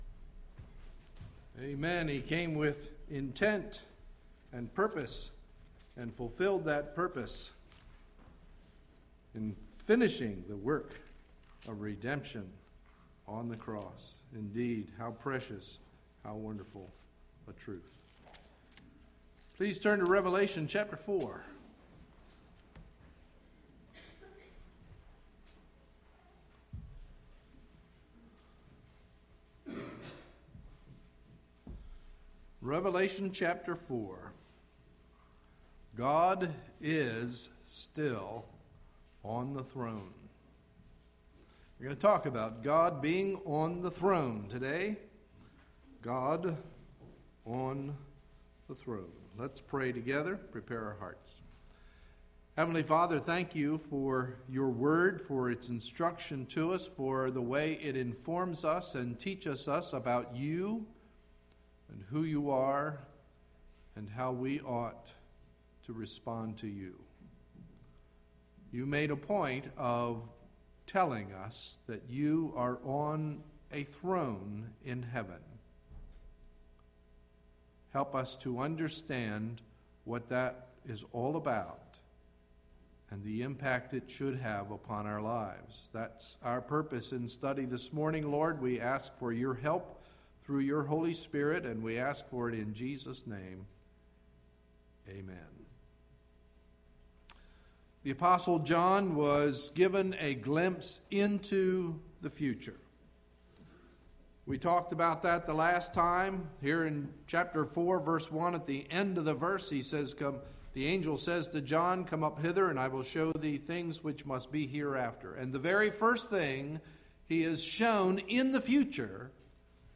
Sunday, May 15, 2011 – Morning Message